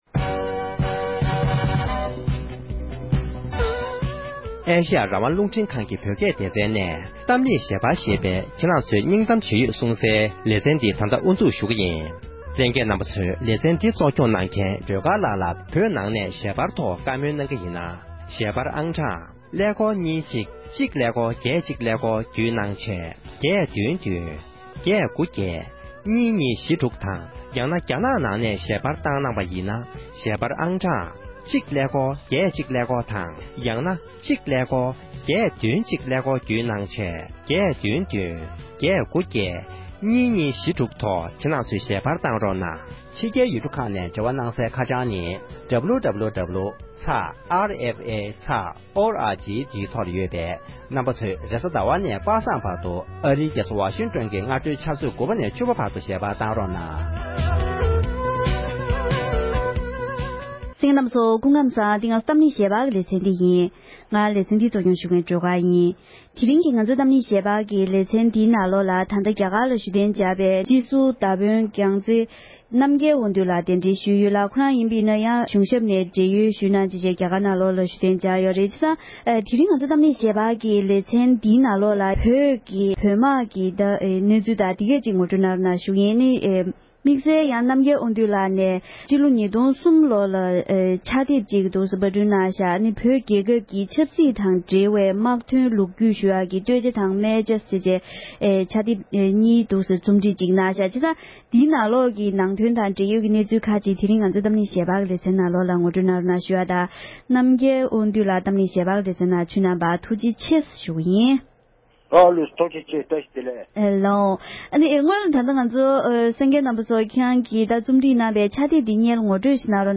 བཀའ་འདྲི་ཞུས་པ’འི་ལེ་ཚན་དང་པོ་འདི་གསན་རོགས་གནོངས༎